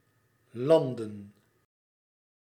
Landen (Dutch pronunciation: [ˈlɑndə(n)]
Nl-landen.ogg.mp3